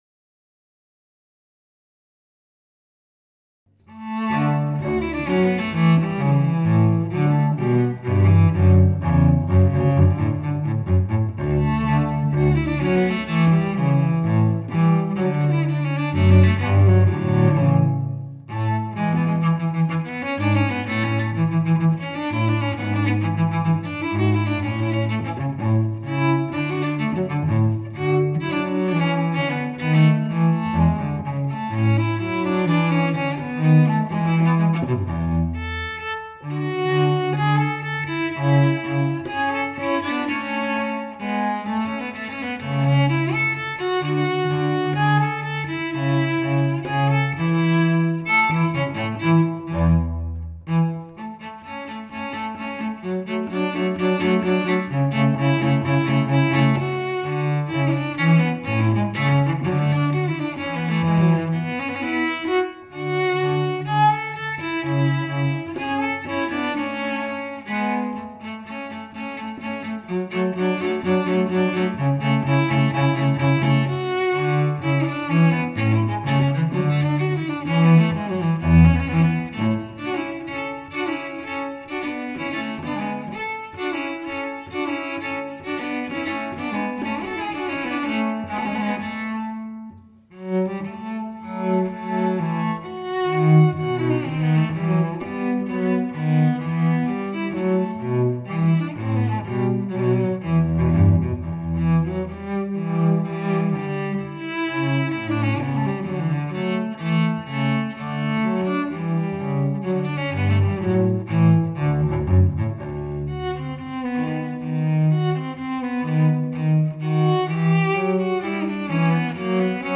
arrangés pour 2 violoncelles